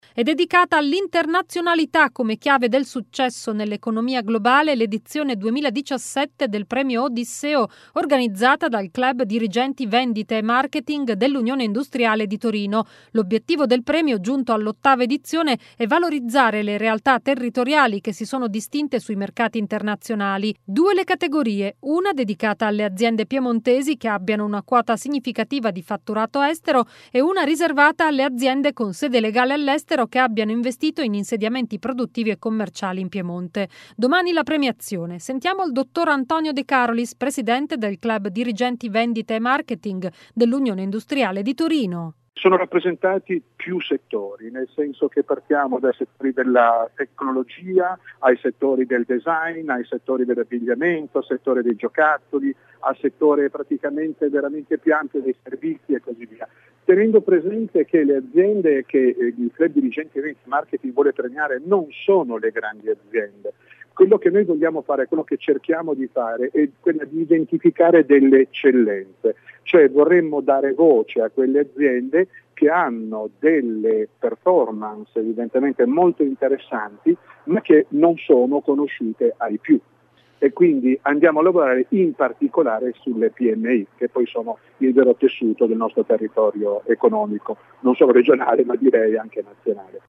Premio Odisseo – Servizio Radio Veronica One
servizio-premio-odisseo-radio-veronica.mp3